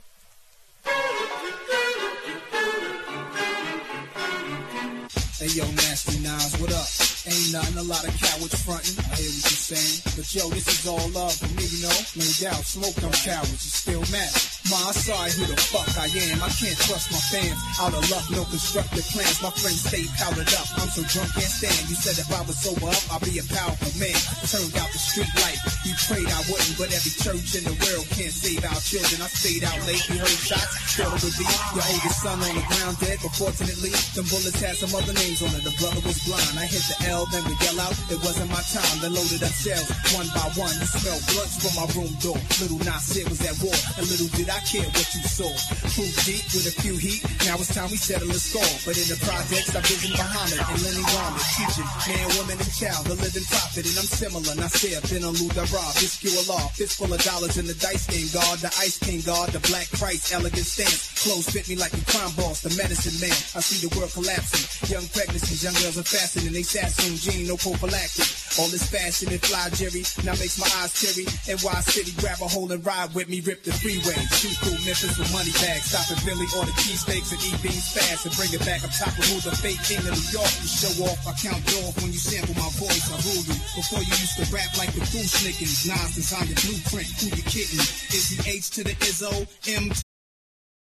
大ネタ使いのナイストラックレアプロモ盤!!